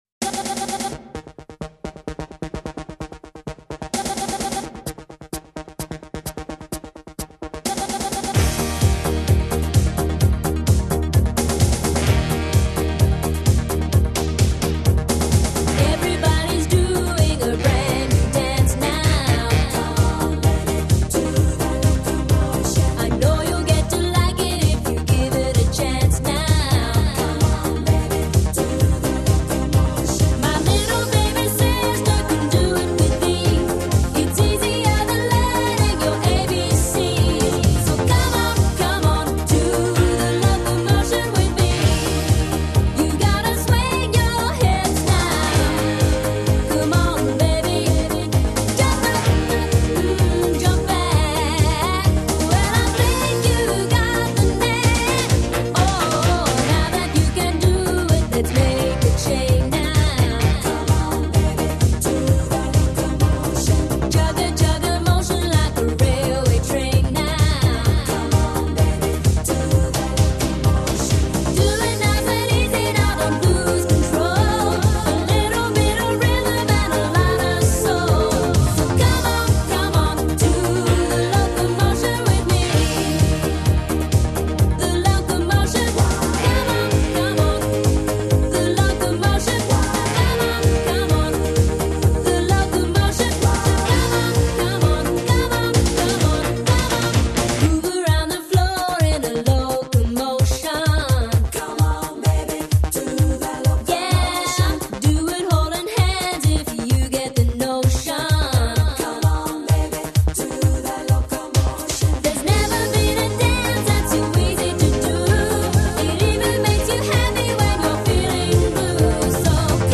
EuroDance 90-х